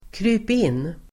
Ladda ner uttalet
Uttal: [kry:p'in:]
krypin.mp3